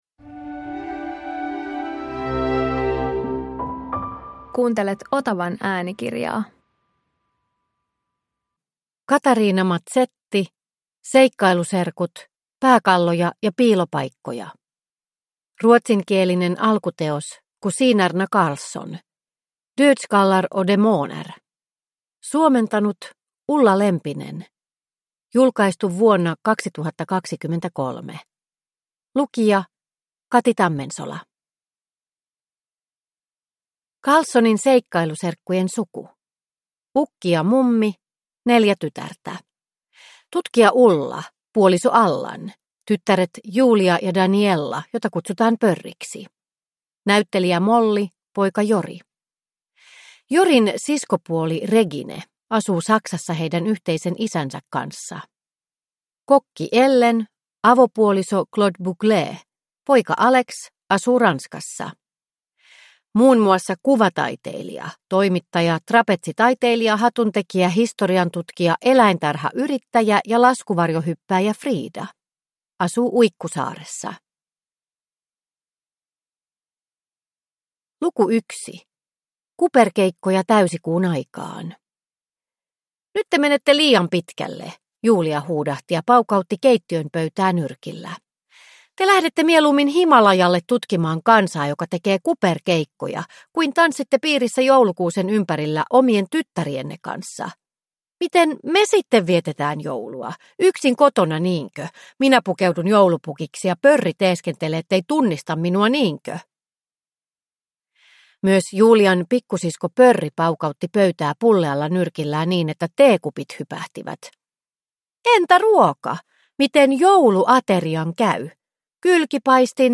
Pääkalloja ja piilopaikkoja – Ljudbok – Laddas ner